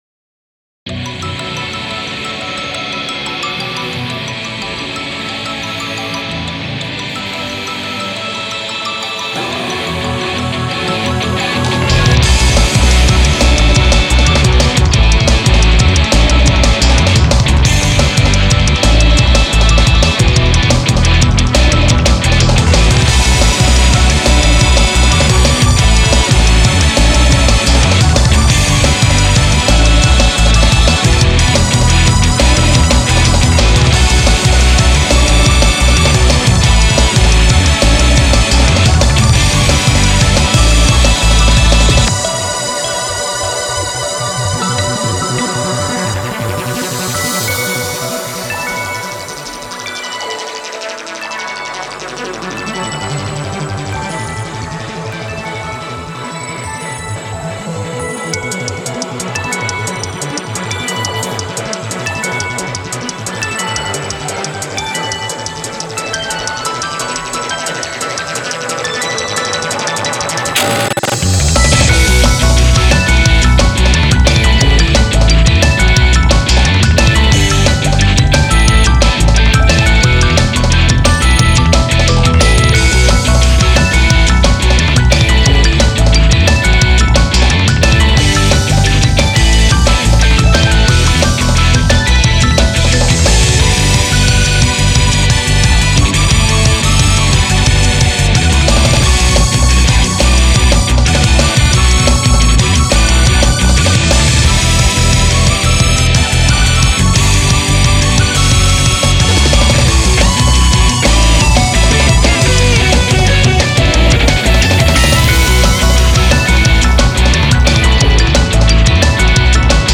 Industrial , Melodic , Late-night Flight